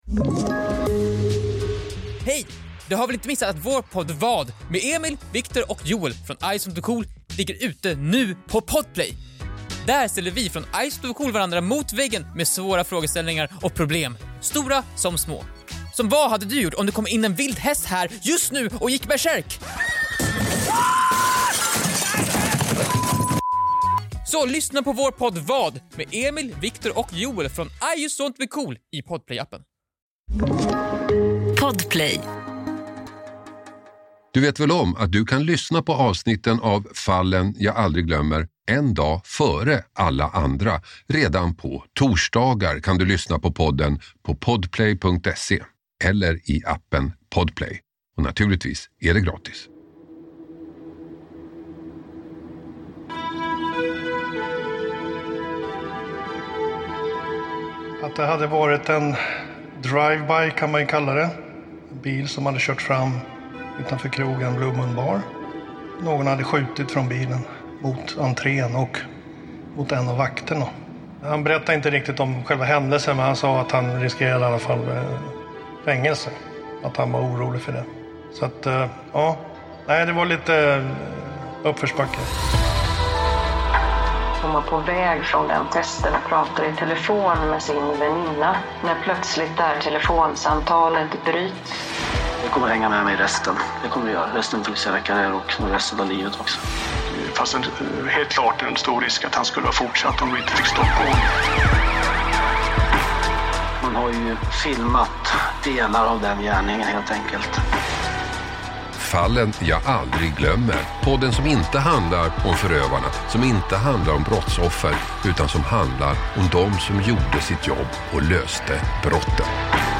Hasse Aro intervjuar